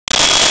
Sci Fi Beep 10
Sci Fi Beep 10.wav